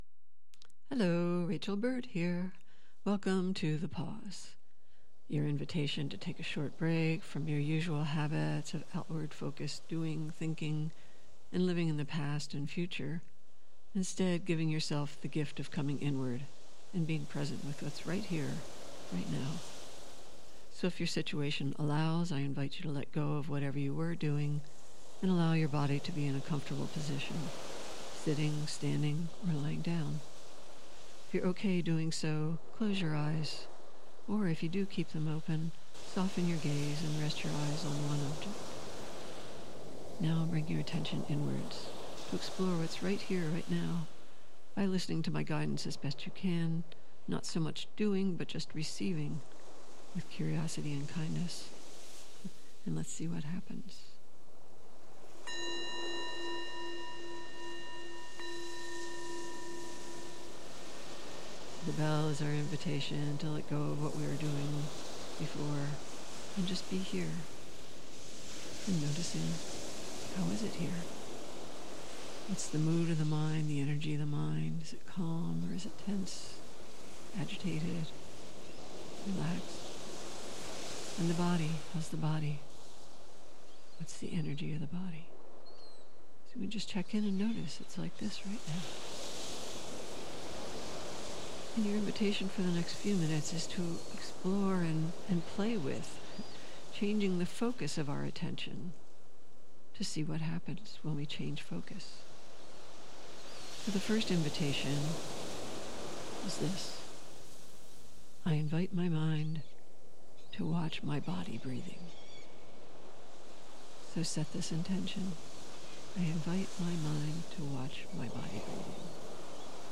Meditation practice using changing focus of attention